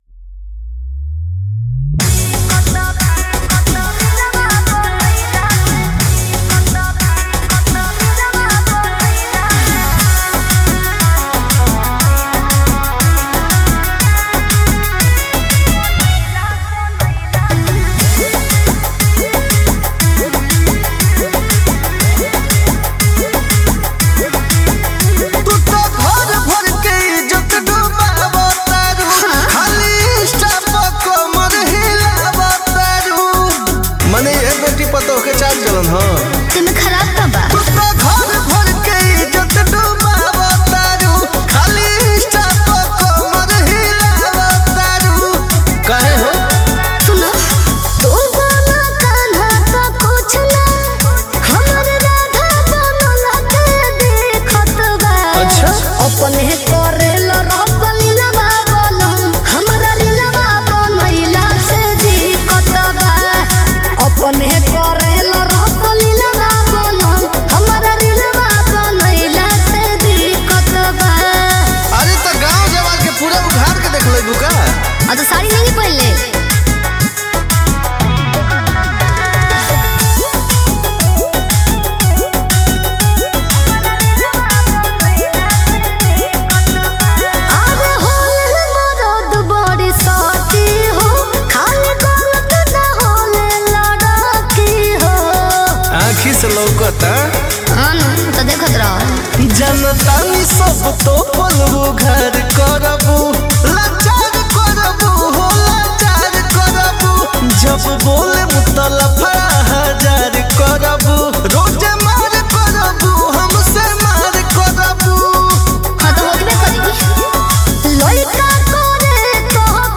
Category: Bhojpuri